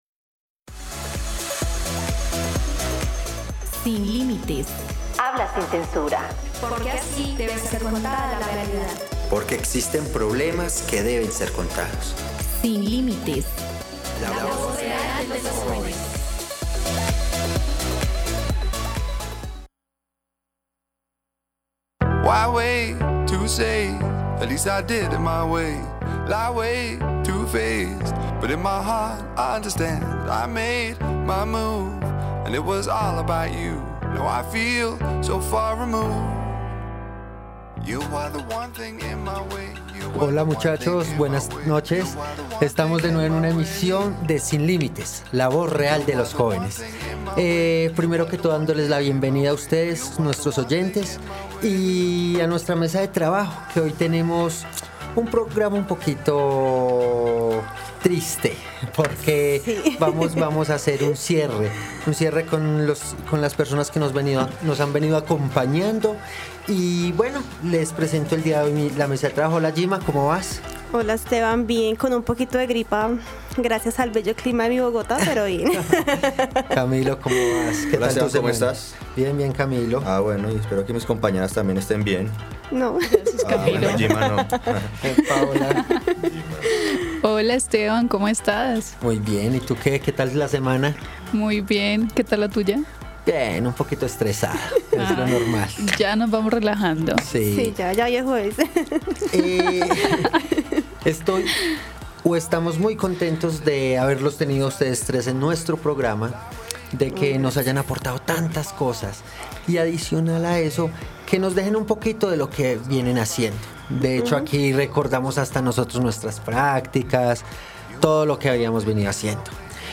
¡Bienvenidos a Sin Límites, un programa radial diferente; en donde solo se piensa en ti!